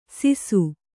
♪ sisu